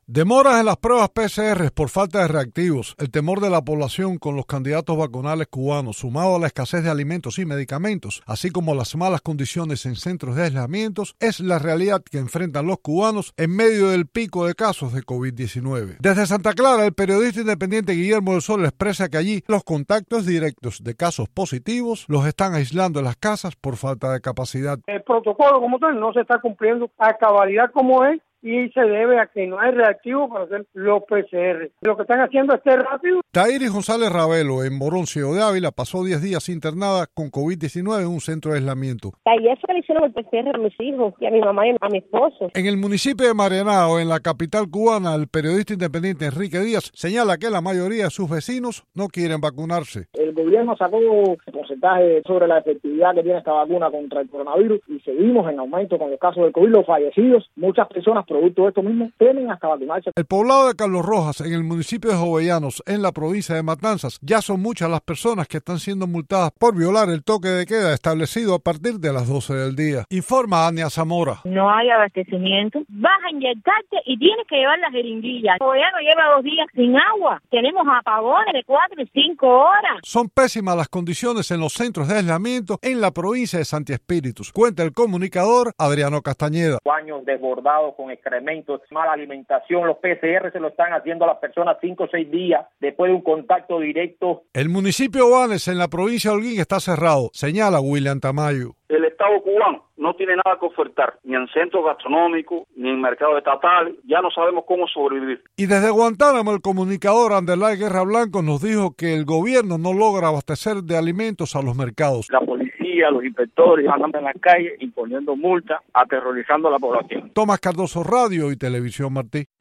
recogió testimonios desde la isla